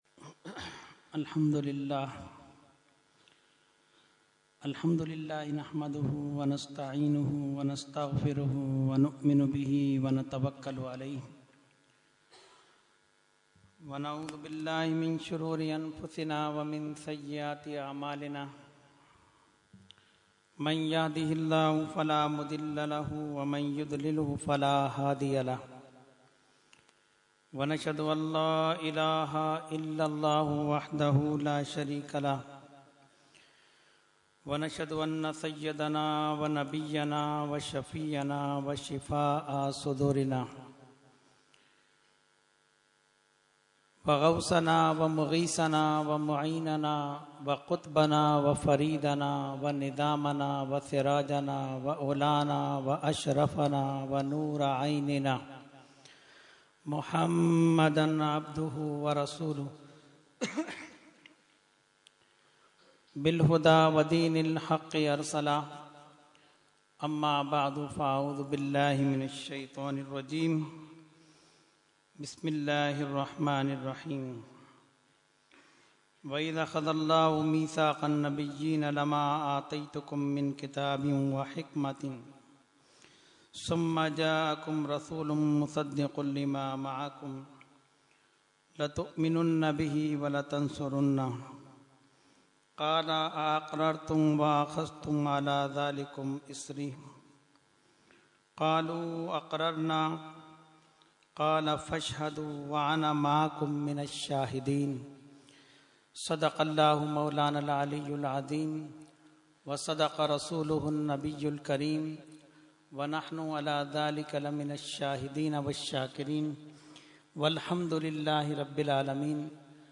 Category : Speech | Language : UrduEvent : Subhe Baharan 2014